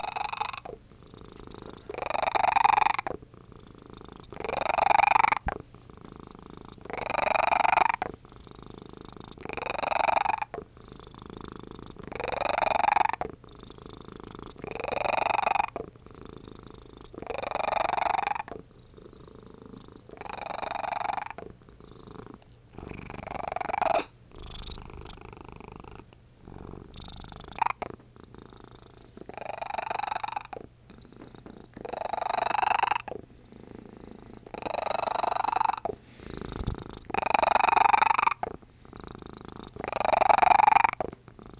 Calliope the cat
She's quite the "helper" when a lap is available but her purr is perhaps her most distinguishing characteristic.
Calliope's Purr.aiff